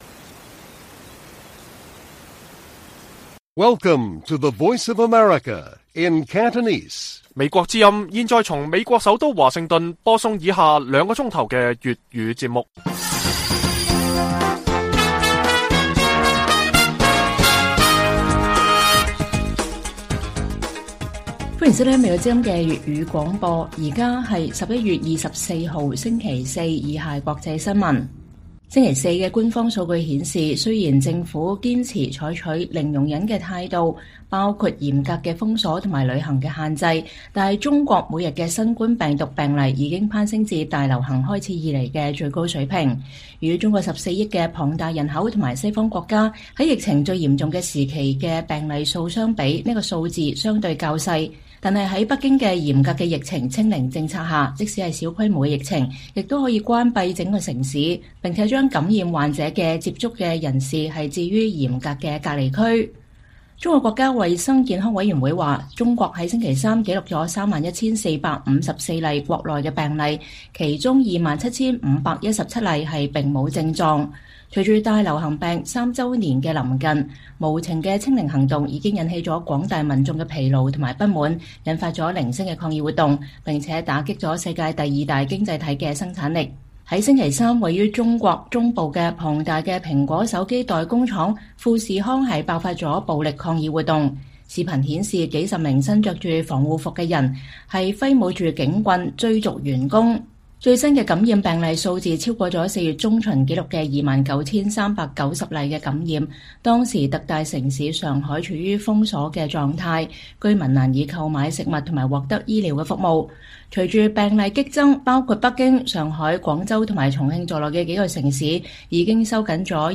粵語新聞 晚上9-10點 : 香港傳媒大亨黎智英欺詐罪成求情指非為金錢沒違反誠信 押後12月10日判刑